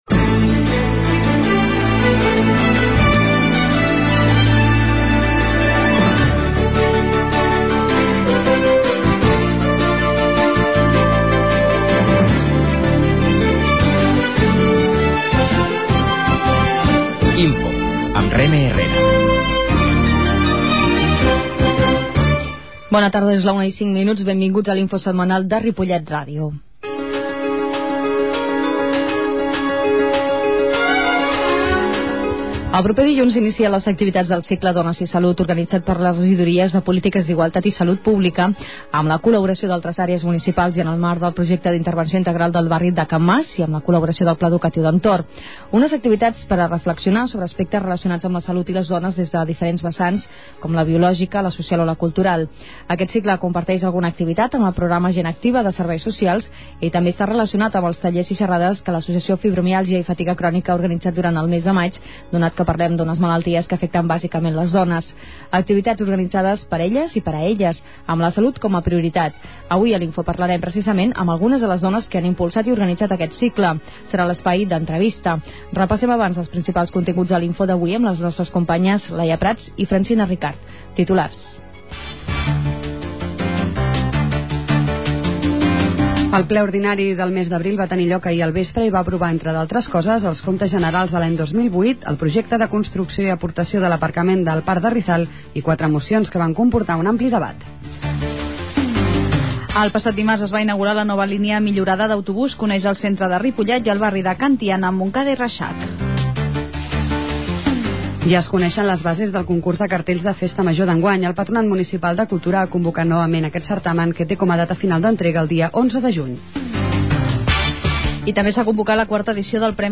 A més, per via telefònica, també ha participat la regidora de Polítiques d'Igualtat, Maria del Mar Viera.
La qualitat de so ha estat reduïda per tal d'agilitzar la seva baixada.